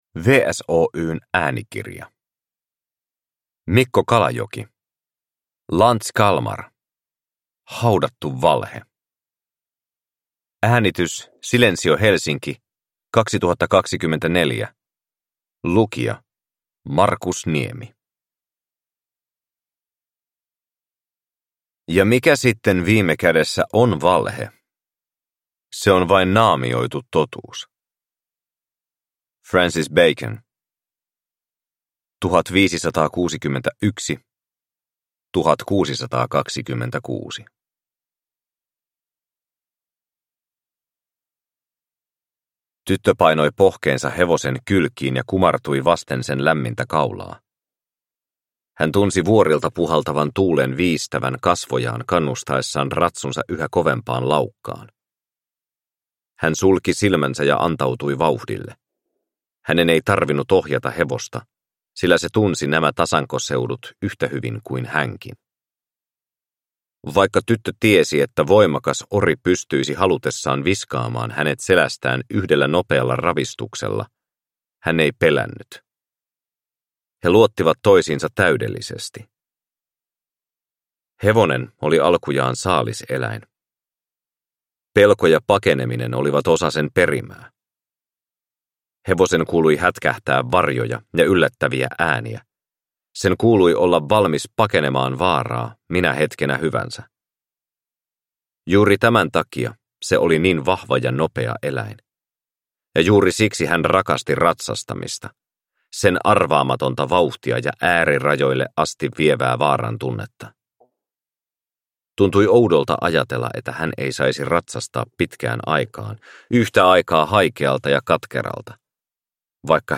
Lanz Kalmar - Haudattu valhe – Ljudbok